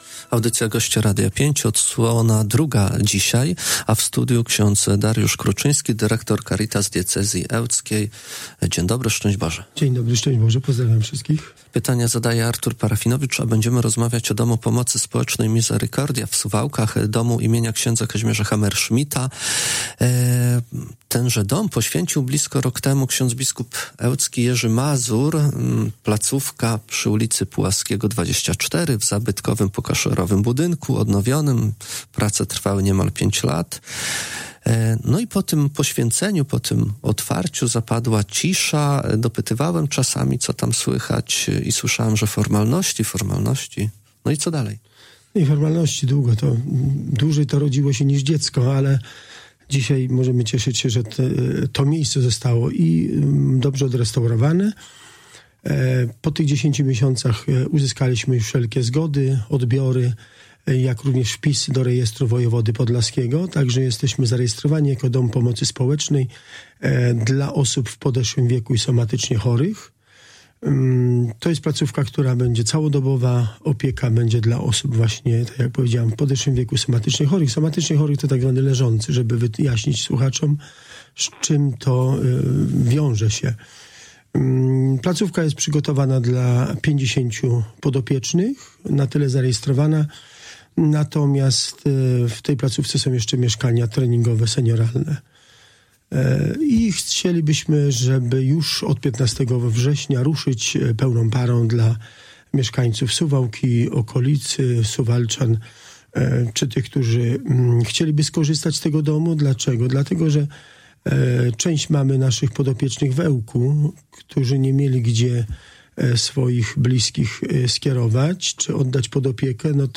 FORMALNOSCI-CALA-ROZMOWA.mp3